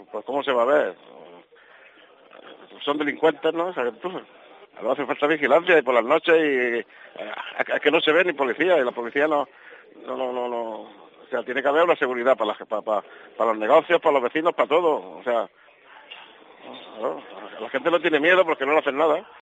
vecino del barrio de Rocafonda de Mataró denuncia la falta de seguridad.